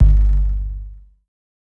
KICK_WORMSUB.wav